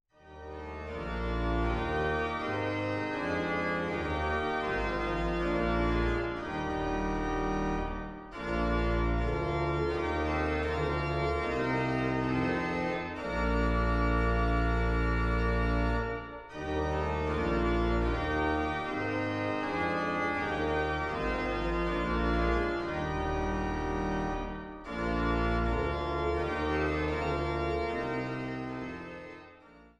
Barockoboe
Trost-Orgel in Großengottern